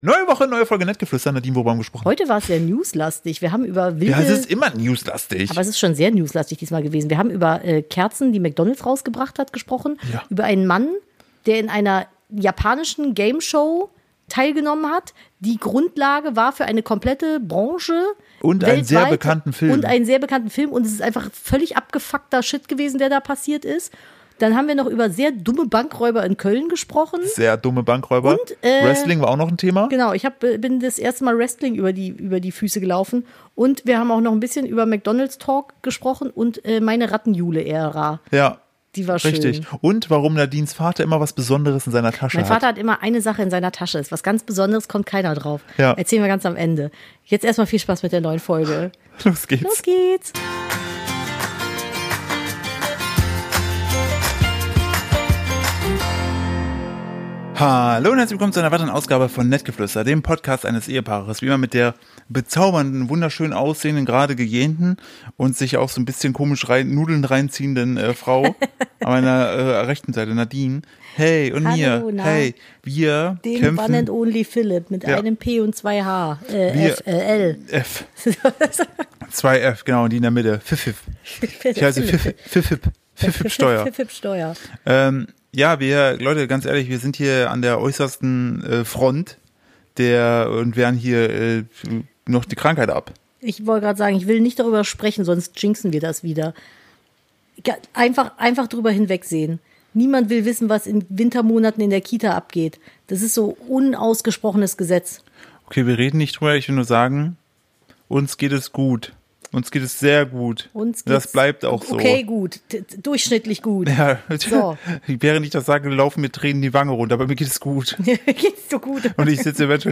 Wir sind ein Familien- und Ehepaar Podcast und nutzen die Zeit, um alltägliche Dinge zu besprechen, für die uns oft einfach die Zeit fehlt.